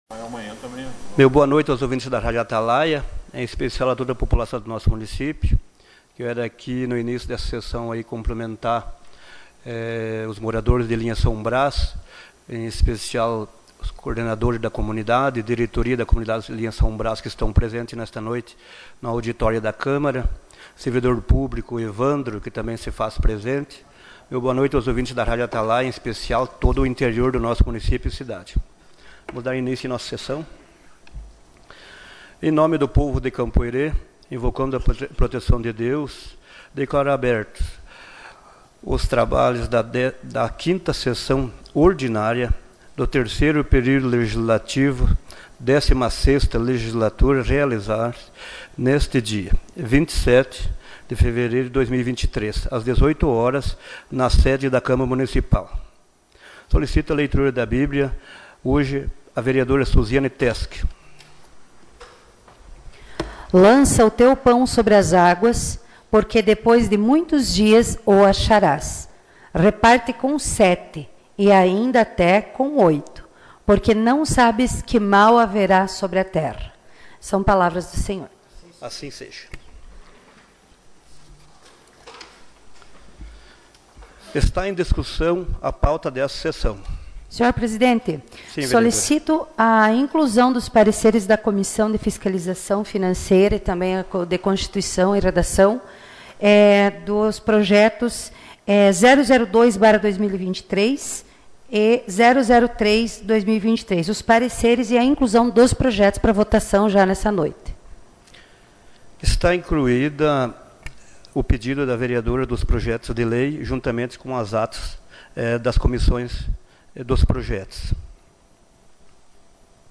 Gravação das Sessões